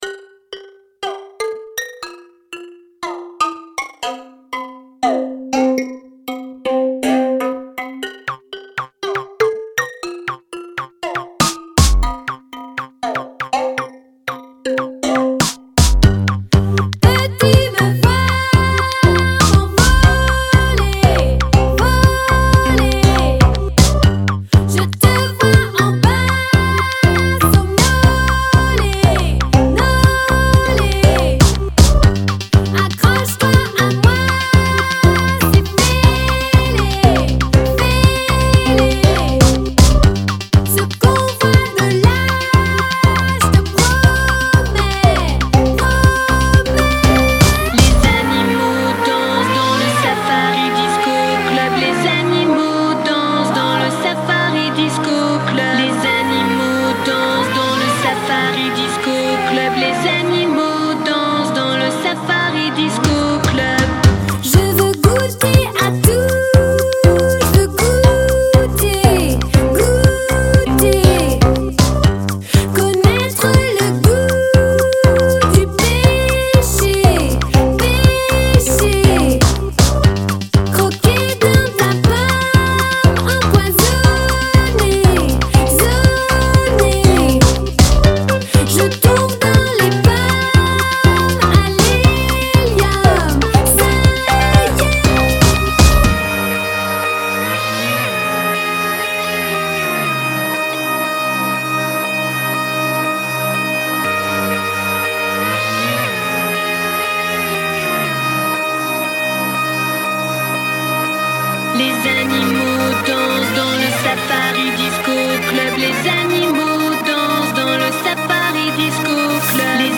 electro pop